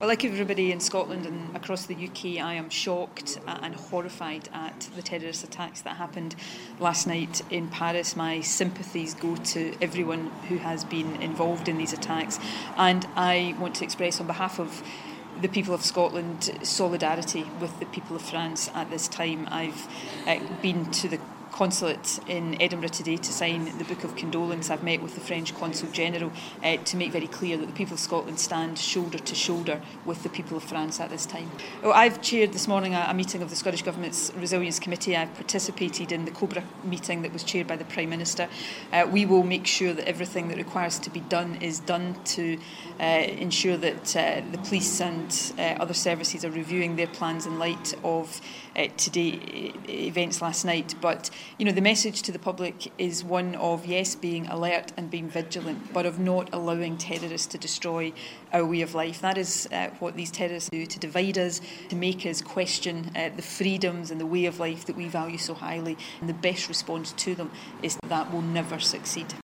The First Minister speaks to us in the wake of the Paris attacks.